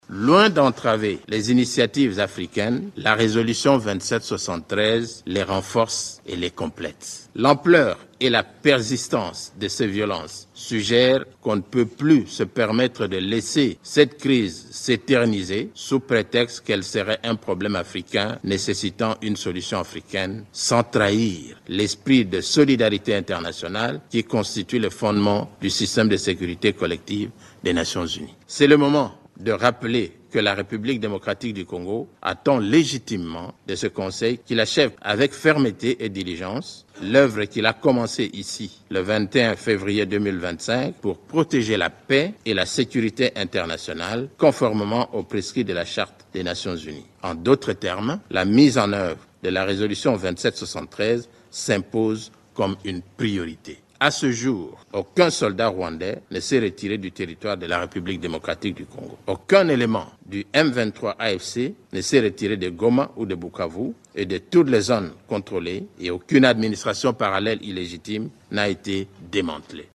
Le représentant permanent de la République démocratique du Congo (RDC) à l’ONU, Zénon Mukongo, a déclaré ce jeudi 27 mars devant le Conseil de sécurité que plus de la moitié de l’armée rwandaise est déployée sur le territoire congolais. Il s’exprimait lors d’un exposé sur la situation sécuritaire en RDC.